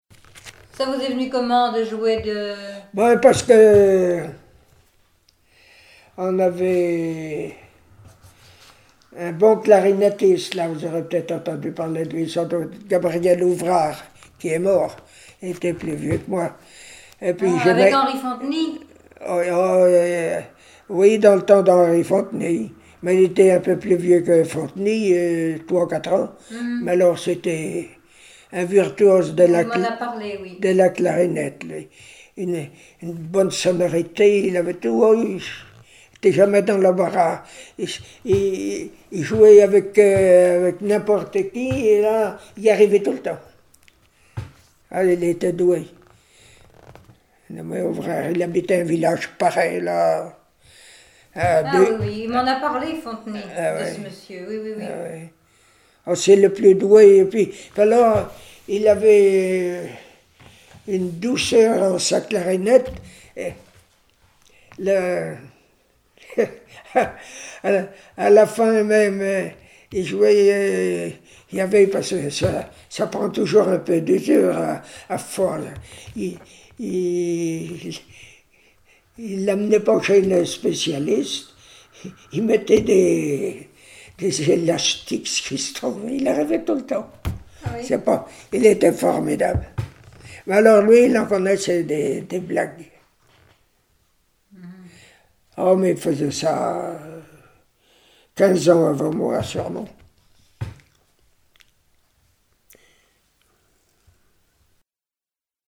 Enquête Arexcpo en Vendée-Pays Sud-Vendée
Catégorie Témoignage